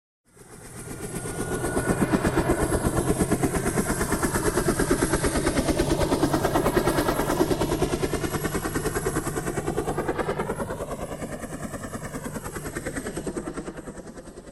Helecopter-sound-HIingtone
helecopter_25152.mp3